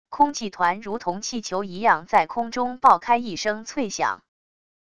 空气团如同气球一样在空中爆开一声脆响wav音频